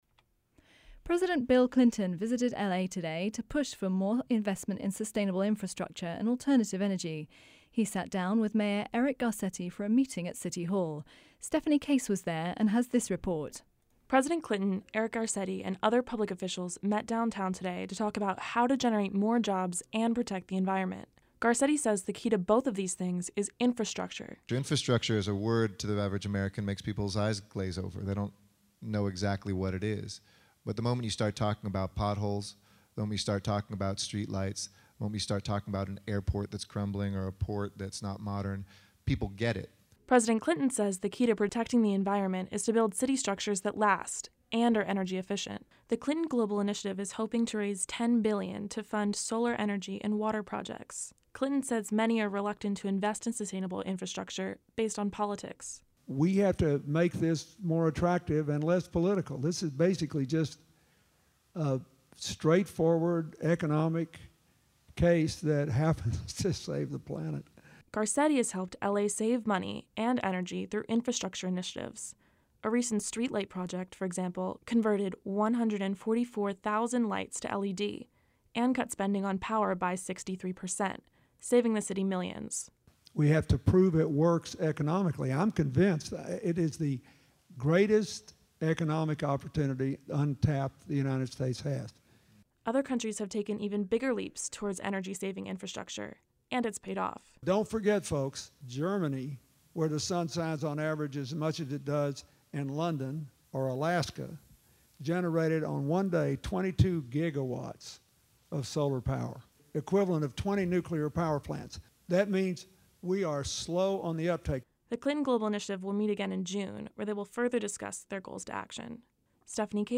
President Bill Clinton visited Los Angeles City Hall Thursday to raise support for building resilient, sustainable infrastructure.  Clinton says more investments in infrastructure projects would boost the job market and help the environment.  Garcetti, Atlanta Mayor Kasim Reed and others joined a panel with Clinton to discuss these issues.